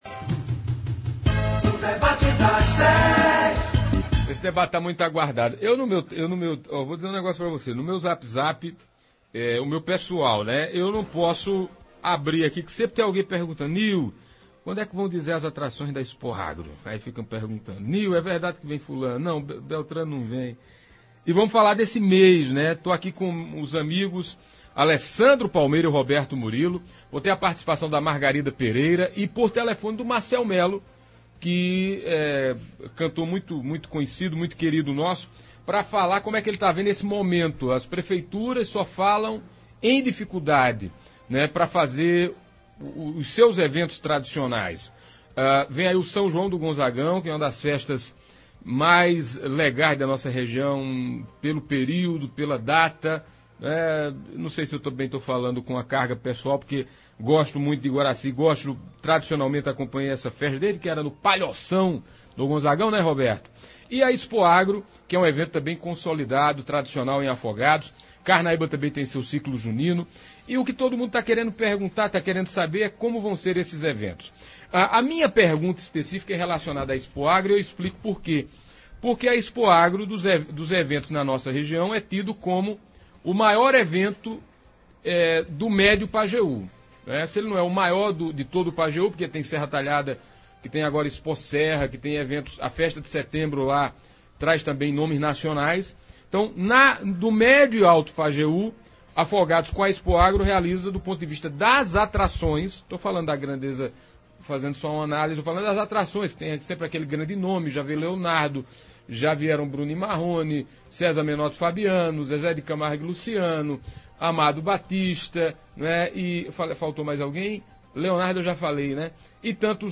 Hoje (10) nos estúdios da Pajeú os Secretários de Cultura de Afogados da Ingazeira, Alessandro Palmeira e de Iguaraci, Roberto Murilo deram uma dimensão de como será o contingenciamento em dois eventos importantes do calendário cultural do Pajeú: a Expoagro em Afogados da Ingazeira e o São João do Gonzagão em Iguaraci.